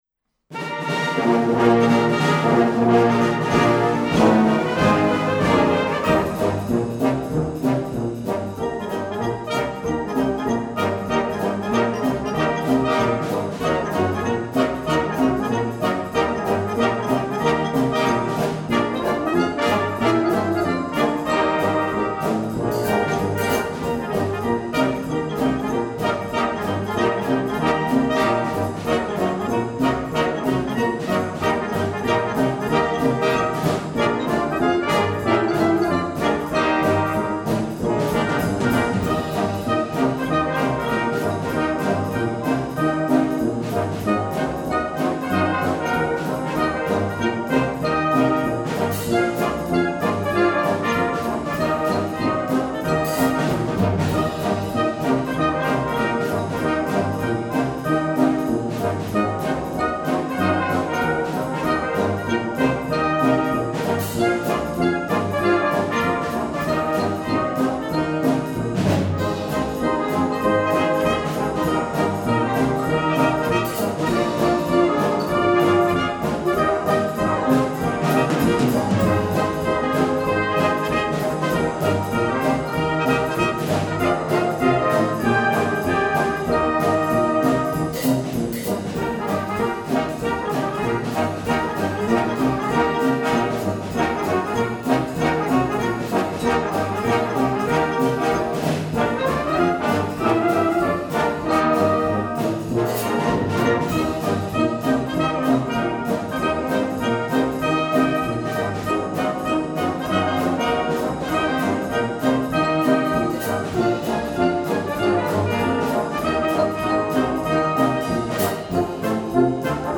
Gattung: Konzertante Unterhaltungsmusik
2:40 Minuten Besetzung: Blasorchester PDF